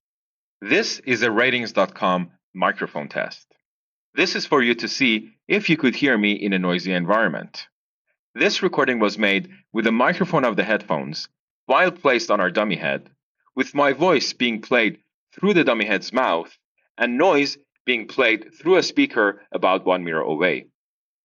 our recording to hear what speech sounds like through the Jabra.